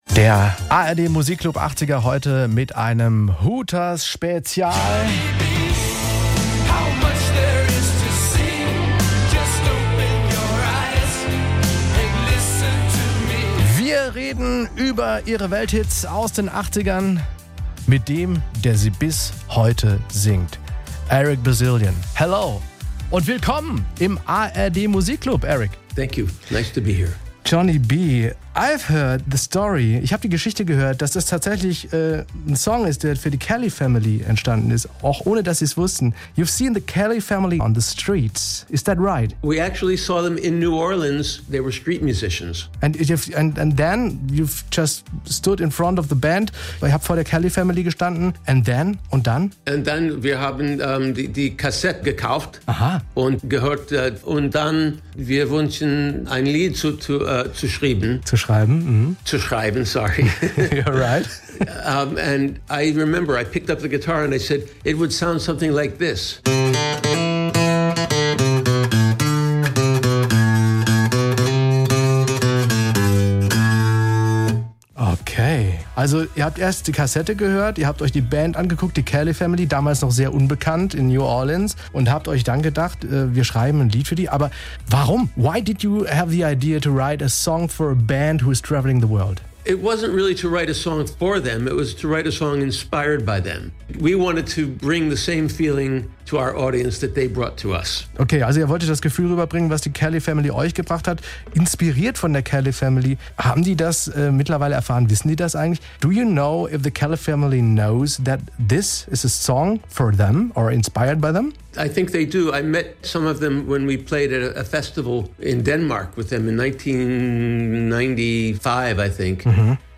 Im Interview mit SWR1 erzählt Eric Bazilian auch die Hintergrundgeschichte zum Song, beziehungsweise die Inspirationsquelle zu "Johnny B.".